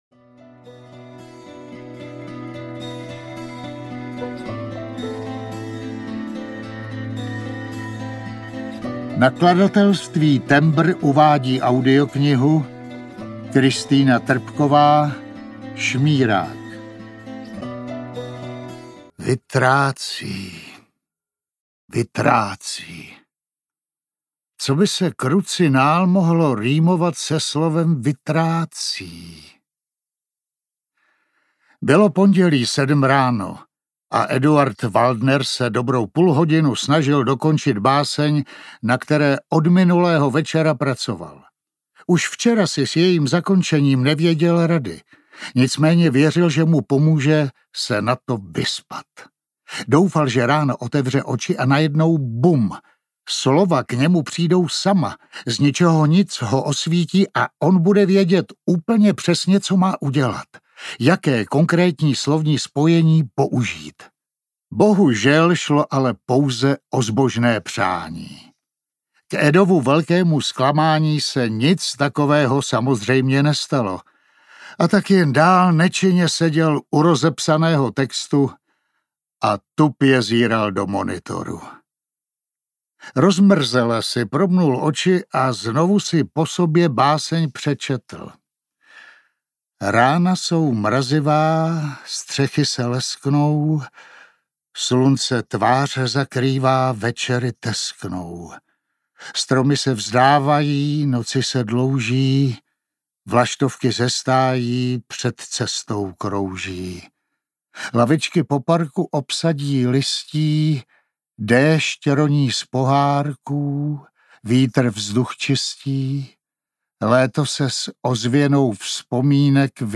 Šmírák audiokniha
Ukázka z knihy